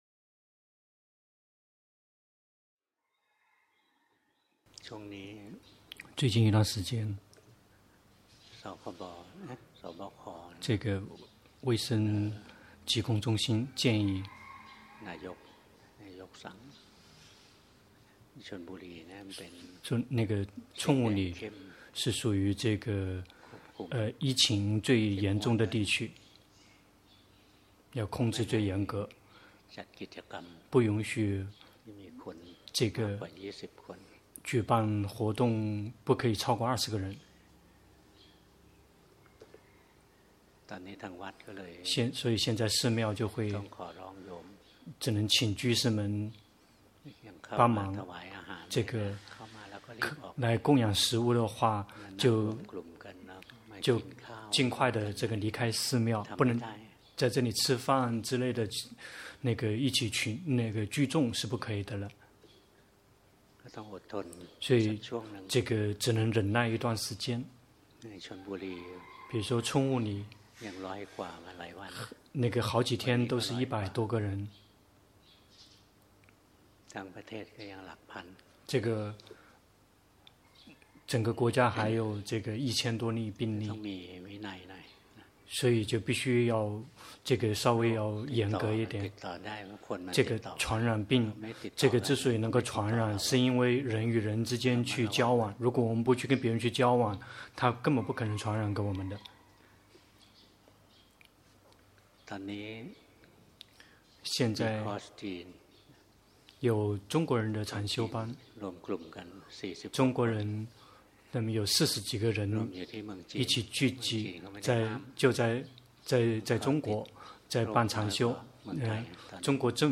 長篇法談｜即使學習《阿毗達摩》，也别小瞧經藏——隆波帕默尊者 - 靜慮林